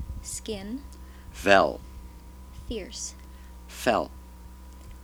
In a separate recording, we get the minimal pairs: /ʋel/ and /fel/ and /dɔf/ and /dɔs/, proving that /f/ and /s/ are phonemes of Dutch.
While there are more consonants than this in Dutch, these were the ones present in the word list recordings from the UCLA phonetics lab archive.